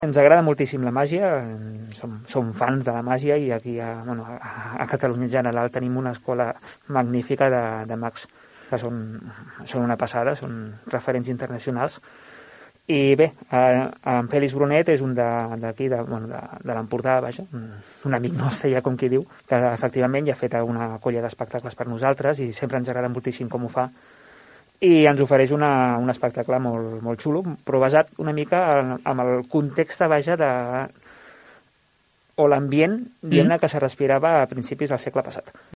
N’ha parlat, en declaracions a aquesta emissora